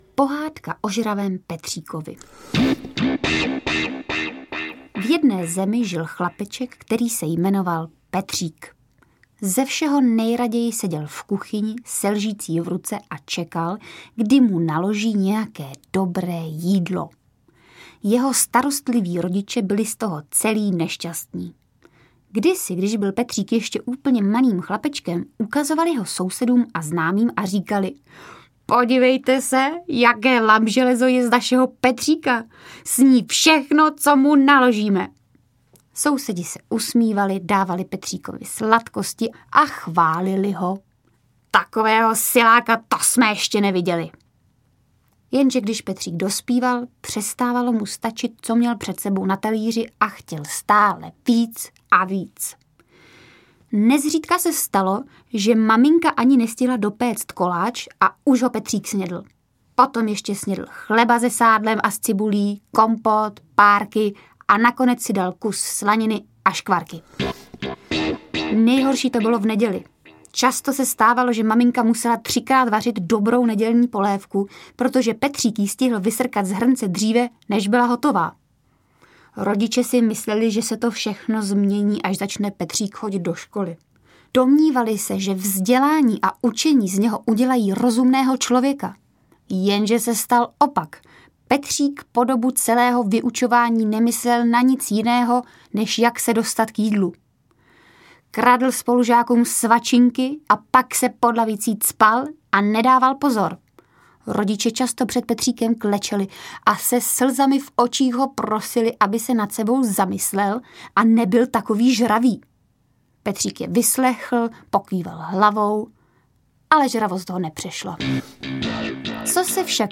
Ukázka z knihy
Interpretka Aňa Geislerová komentuje brutální příhody dětských hrdinů svým něžným hlasem, jakoby ony groteskní historky neposluchů byly naší samozřejmou a každodenní zkušeností. Nekárá, nenabádá, jen informuje o podivuhodných událostech, které se kdesi - kdysi staly - nestaly, s bezprostřední vazbou k nadsázce.
Zvláště povedené jsou její interpretace protivných učitelek, rozčilených ředitelů škol nebo lékařů podivínů.
• InterpretAňa Geislerová